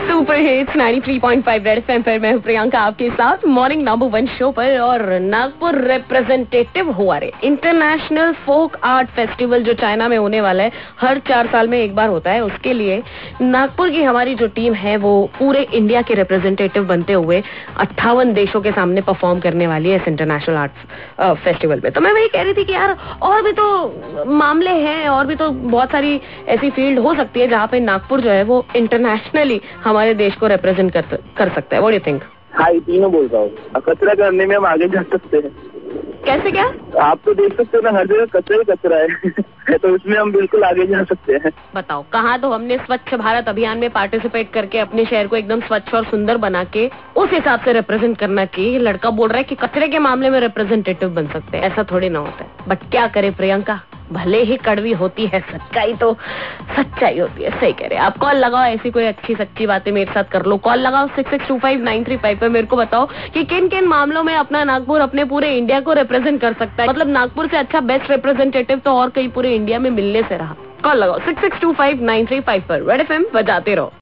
1AUG_L11_NAGPUR REPRESENTING INDIA IN INTERNATIONAL FEST CHINA_CALLER INTERACTION